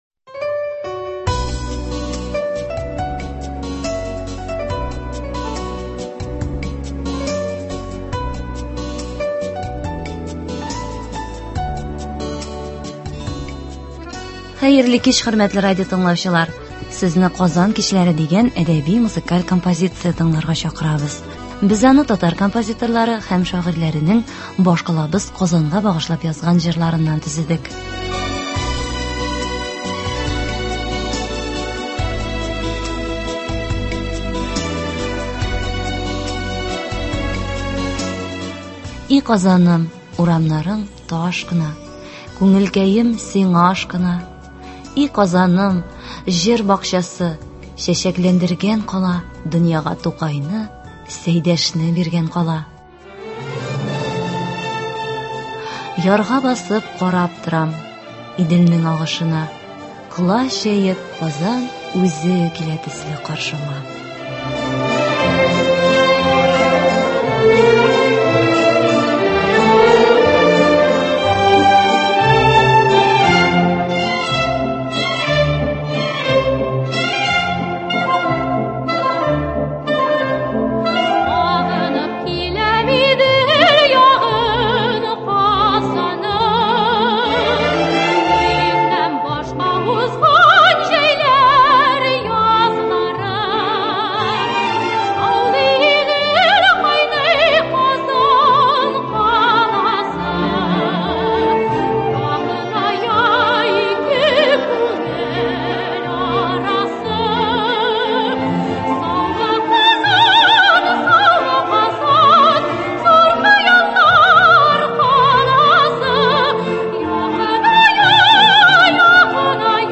Әдәби-музыкаль композиция.